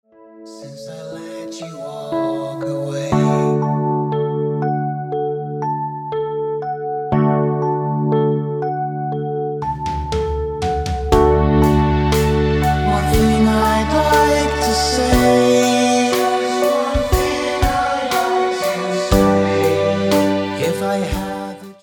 Haunting Ballad